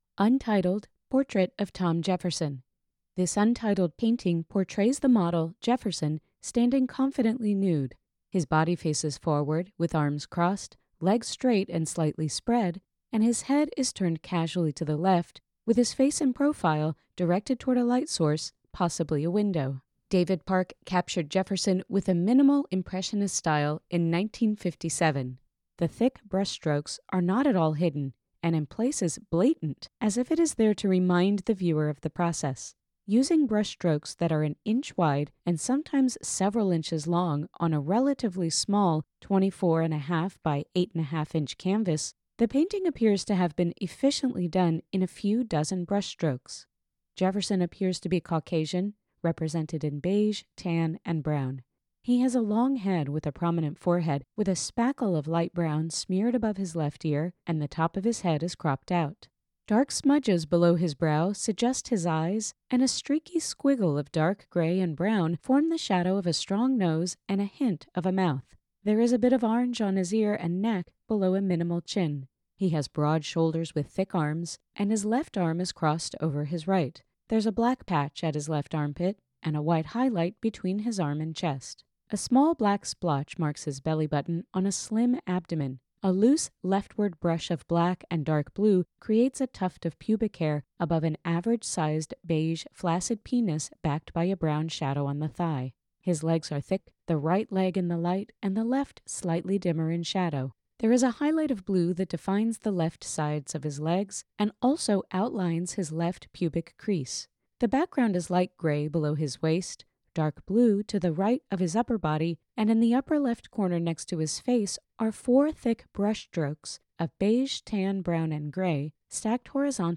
Audio Description (02:07)